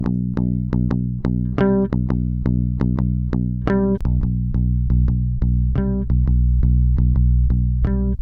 S čímž souvisí další vlastnost - čistý zvuk -> preamp vypnutý a preamp na rovno se zásadně liší - dost véčkuje - lze to na eq dotáhnout, ale už se člověk dostává na hraniční hodnoty.